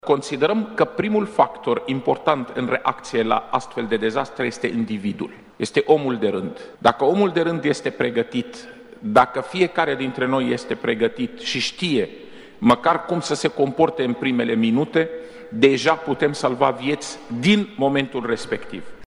Secretarul de stat în MAI, Raed Arafat, a declarat, astăzi, că lumea se așteaptă de la sistemele de urgență să facă „minuni” în cazul producerii unui cutremur, însă șeful Departamentului pentru Situații de Urgență a subliniat că dezastrele sunt imprevizibile și un rol important îl are educarea populației: